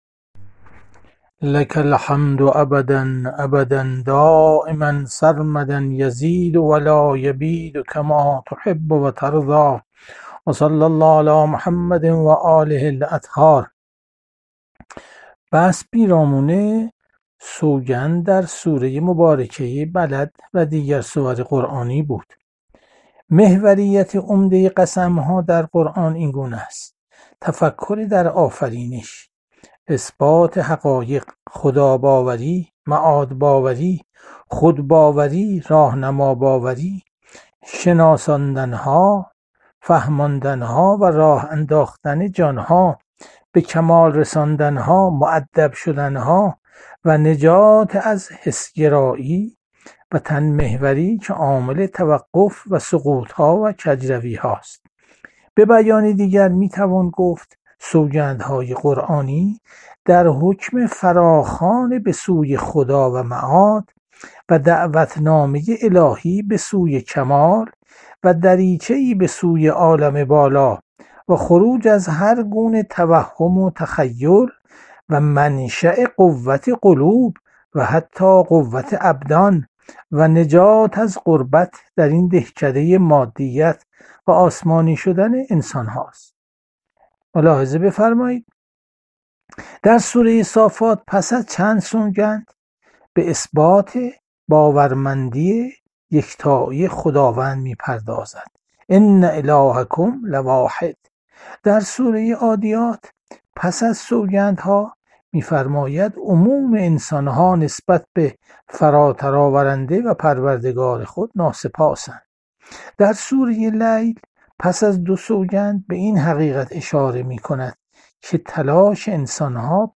جلسه هفتگی تفسیر قرآن- سوره بلد- جلسه ششم- 21 اسفند 1401